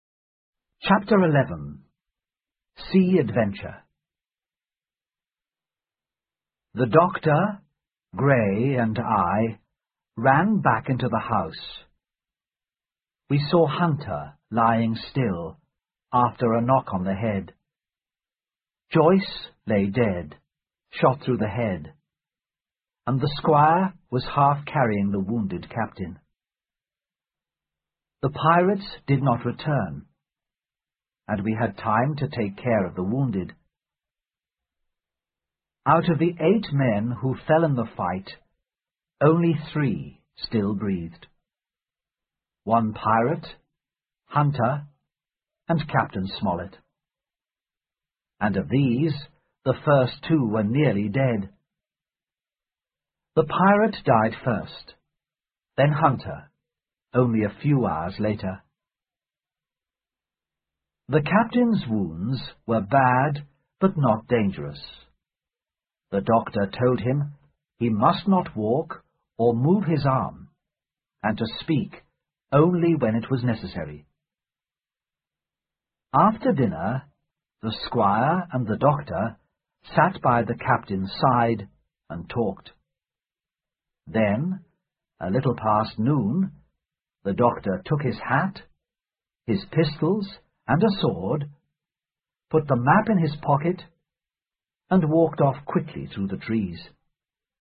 在线英语听力室《金银岛》第十一章 海上历险(1)的听力文件下载,《金银岛》中英双语有声读物附MP3下载